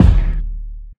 • Long Room Reverb Kick Drum Single Hit D Key 100.wav
Royality free steel kick drum sound tuned to the D note. Loudest frequency: 145Hz
long-room-reverb-kick-drum-single-hit-d-key-100-tZo.wav